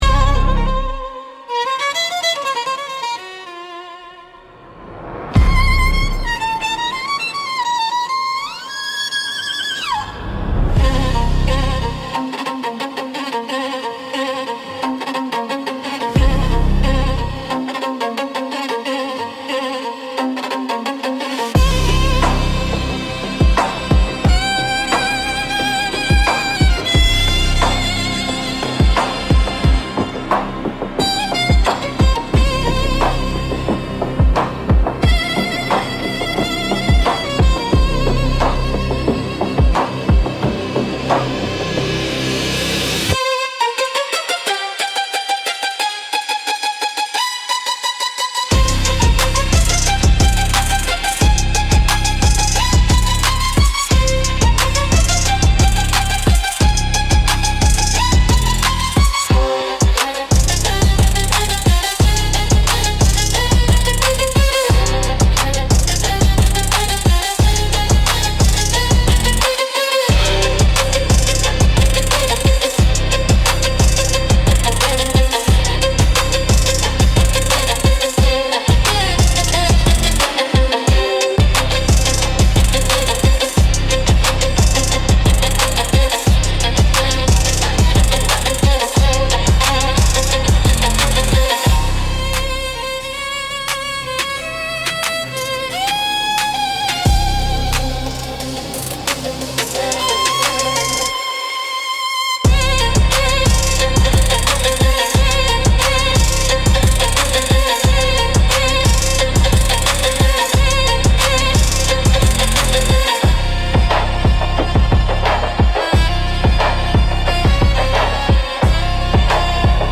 Instrumental - Southern Gothic x Dark Romantic Blues